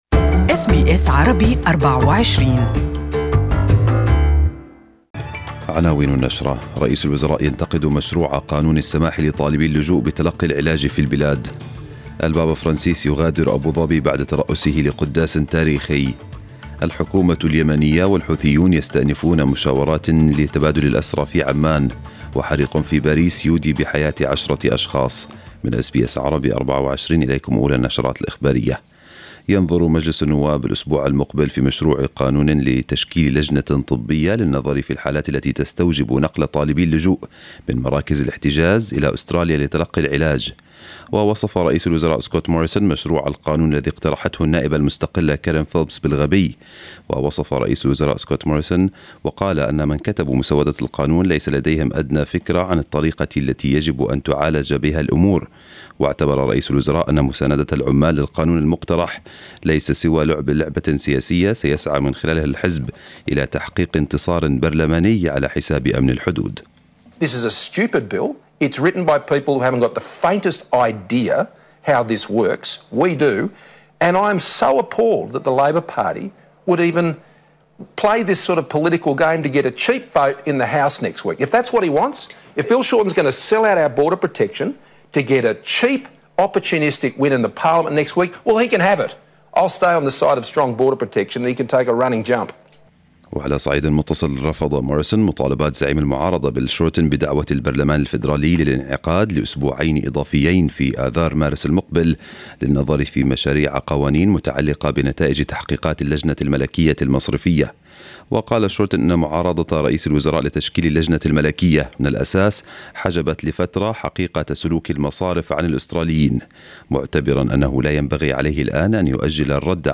نشرة الأخبار باللغة العربية لهذا الصباح